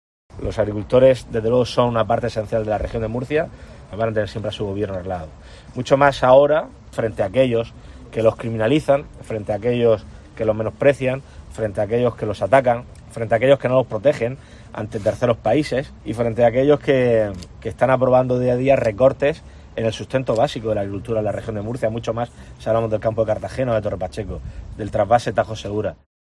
Fernando López Miras, presidente de la Región de Murcia
Durante la clausura de la Asamblea General de Proexport en Torre-Pacheco, López Miras valoró como “fundamental” el papel de las empresas integradas en Proexport en el desarrollo del sector, especialmente para convertirlo en “referente mundial en técnicas agrícolas, gracias a sus esfuerzos para investigar e innovar”.